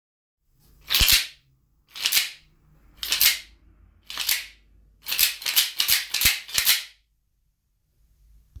ドーナッツ状にカットしたひょうたんを、くの字型の小枝に通したシンプルな楽器です。
前後に振るとその名の通り、「ワッシャン」と豪快な音。
素材： 木 ひょうたん スチール